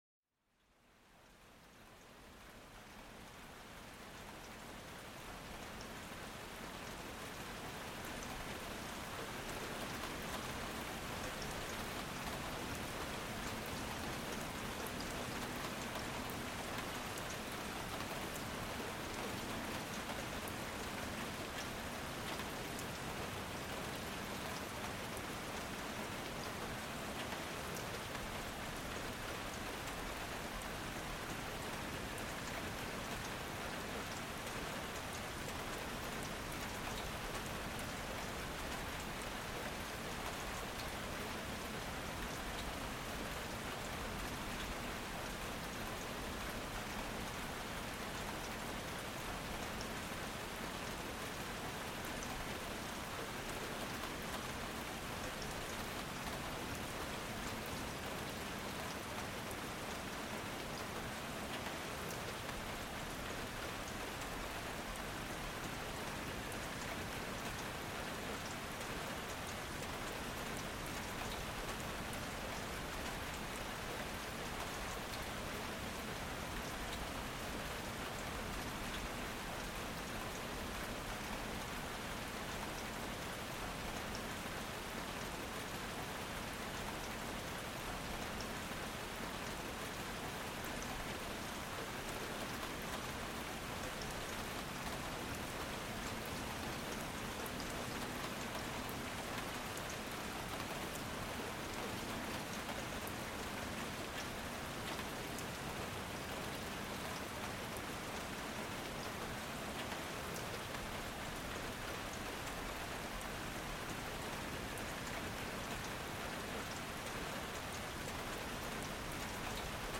Le Son Apaisant de la Pluie : Relaxation et Endormissement Assurés
Plongez dans l'univers apaisant des gouttes de pluie qui tombent doucement sur la terre. Écoutez ce son naturel pour détendre votre esprit et évacuer le stress de la journée.